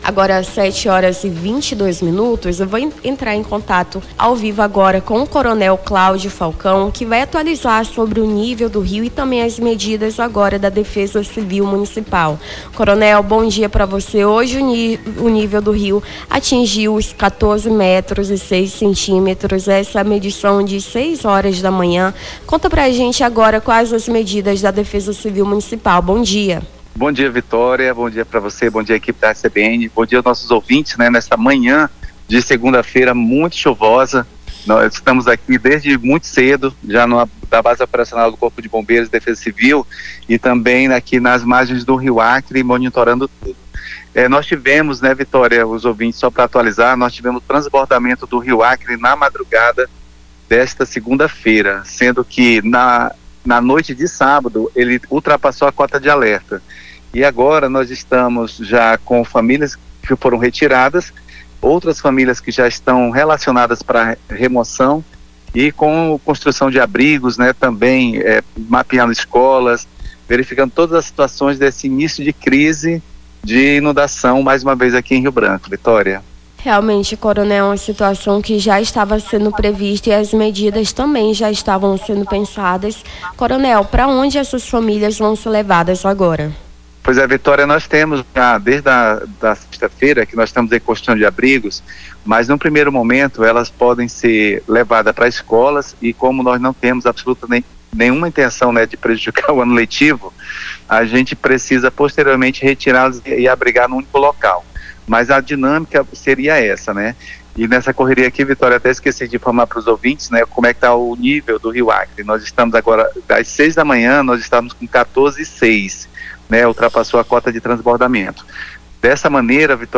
a apresentadora